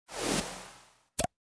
fill_dragonbreath1.ogg